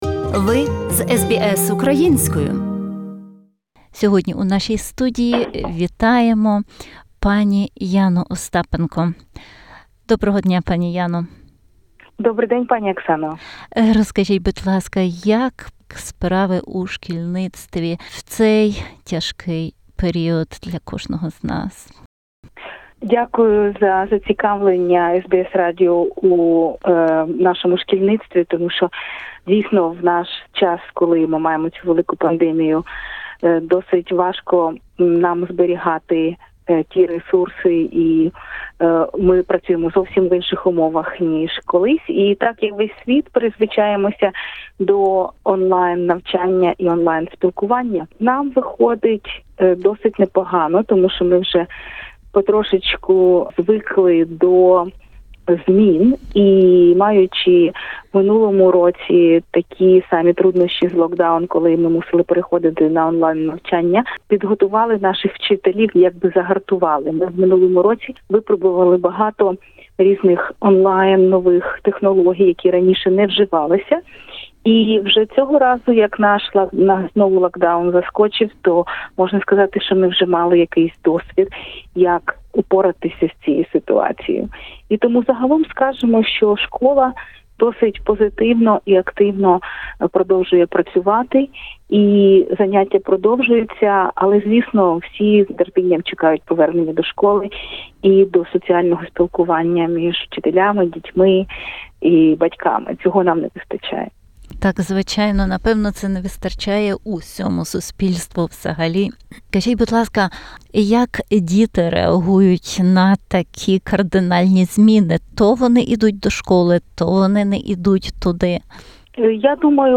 в розмові